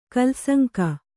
♪ kalsaŋka